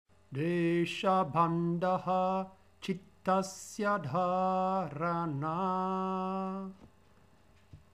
Vibhuti Padah canto vedico